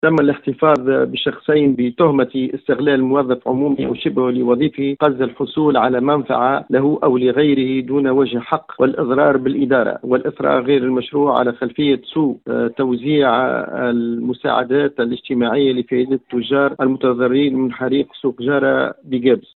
في تصريح ل ام اف ام